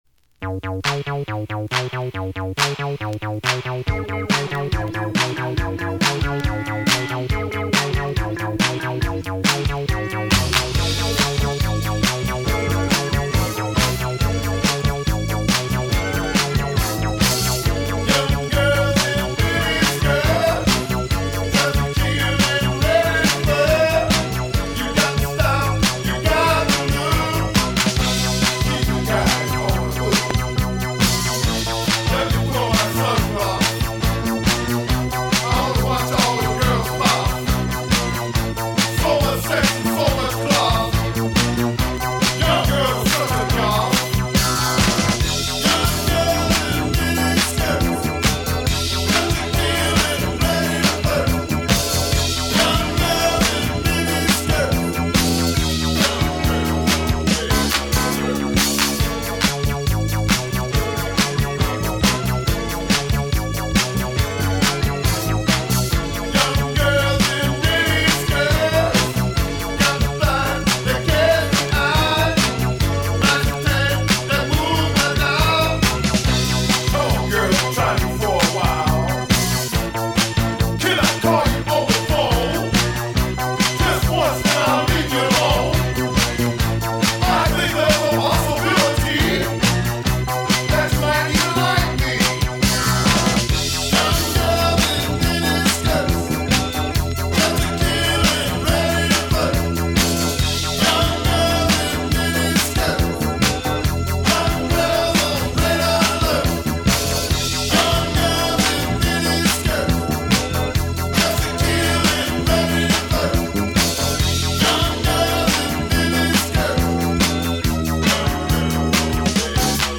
insanely fun macho new wave